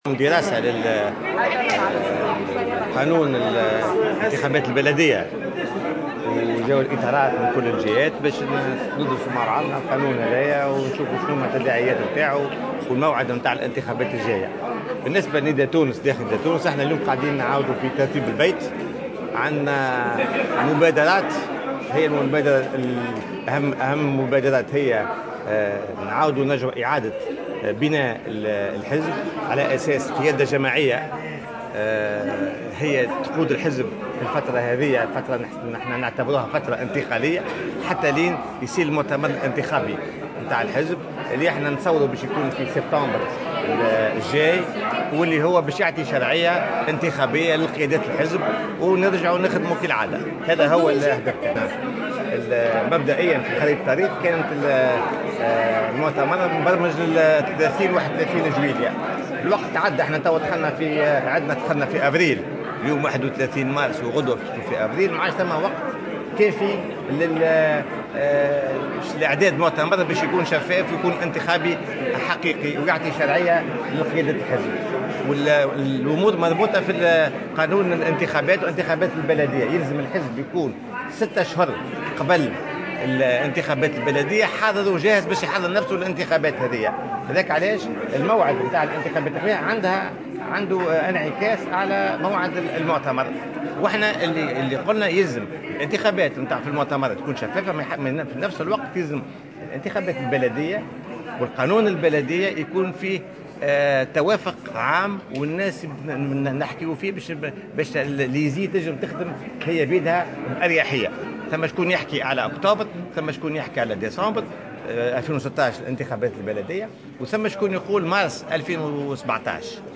واعتبر اللومي أن الانتخابات البلدية ستكون مصيرية لتأسيس الديمقراطية المحلية وذلك على هامش يوم دراسي نظمه "نداء تونس" في العاصمة تونس حول مشروع قانون الانتخابات البلدية.